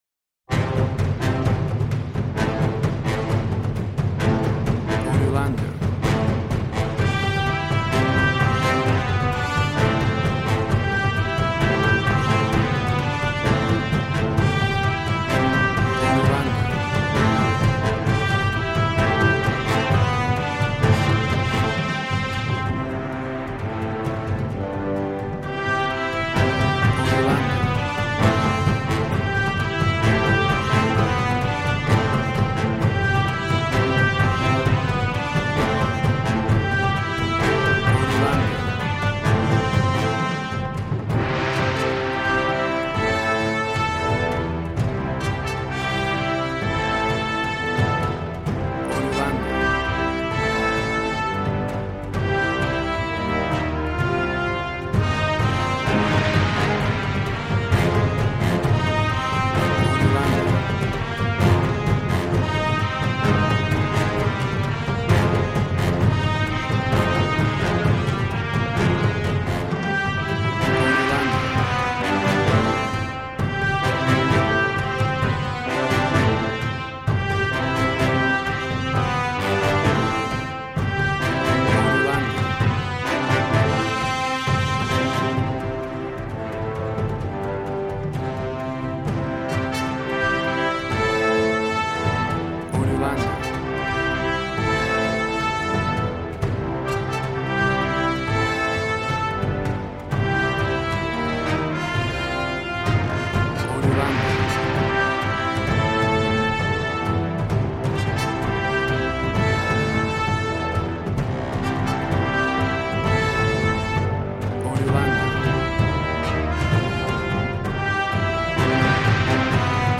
Action and Fantasy music for an epic dramatic world!
Tempo (BPM): 130